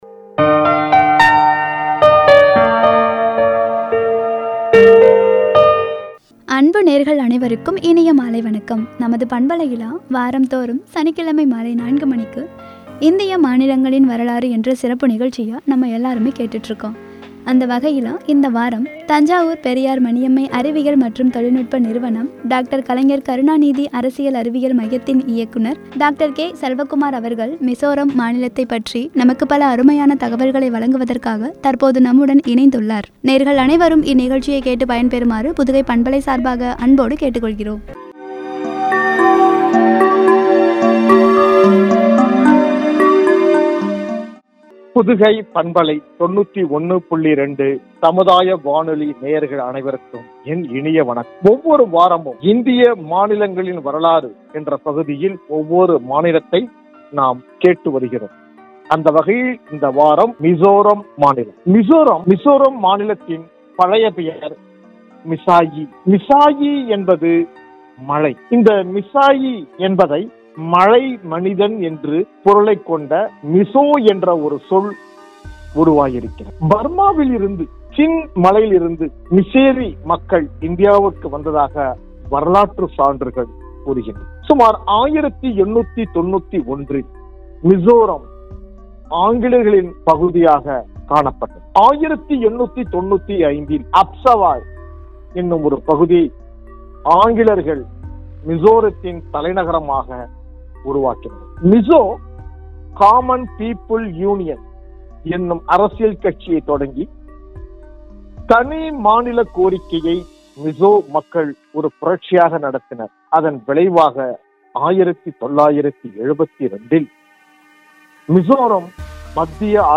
வழங்கிய உரை.